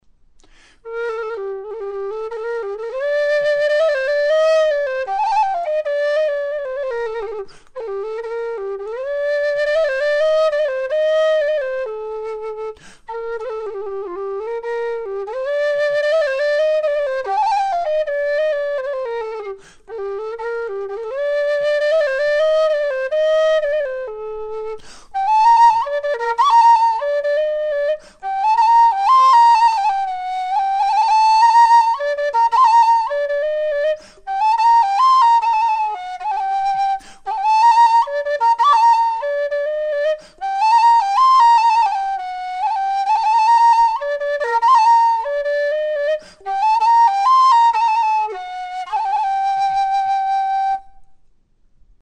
low F whistle - 180 GBP
made out of thin-walled aluminium tubing with 20mm bore
LowF-Valse_A_Gwenfol.mp3